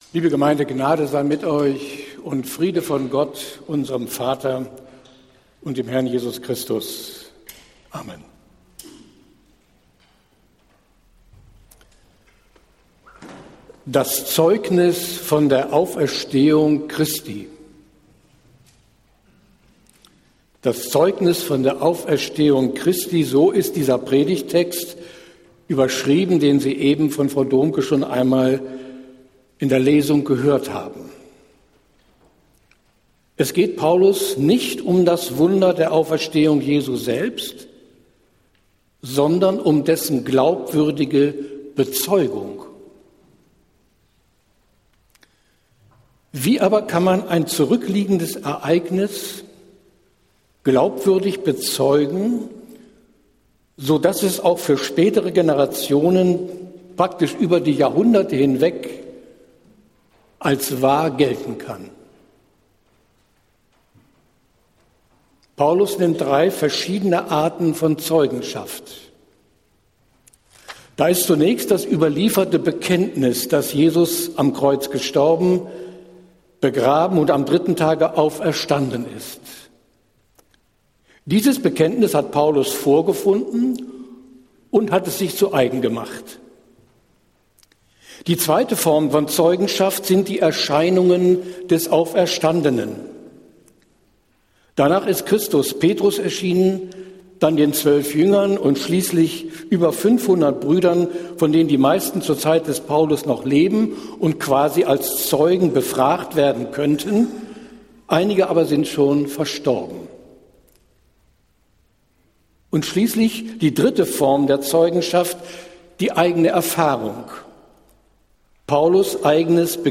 Predigt des Gottesdienstes aus der Zionskirche vom Ostersonntag, 09.04.2023